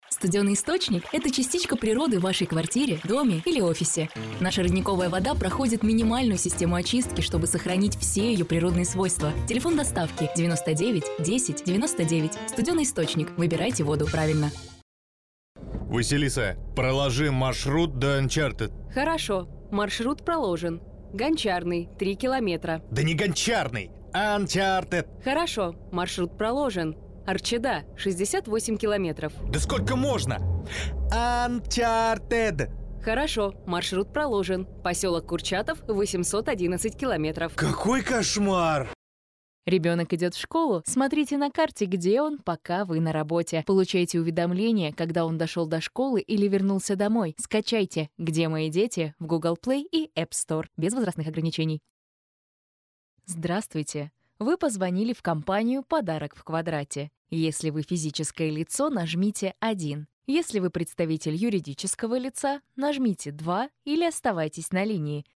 Работаю в различных жанрах - от медитаций до рекламы!
Тракт: Микрофон ARK FET, звуковая карта Audient ID4 MKII, Дикторская кабина